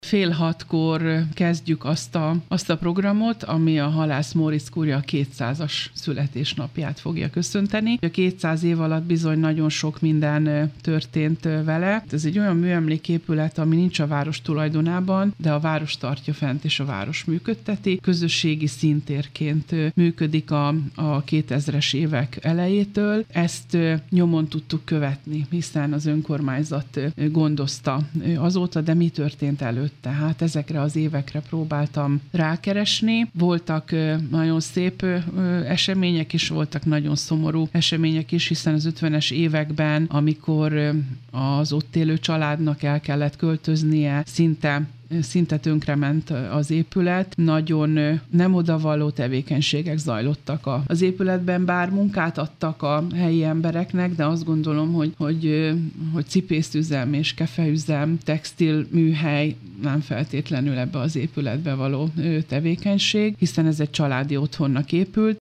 Pálinkásné Balázs Tünde alpolgármestert hallják.